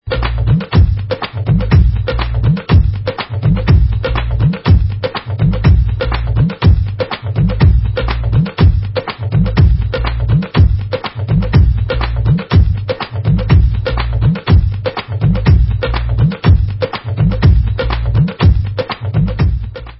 Dholak & Kick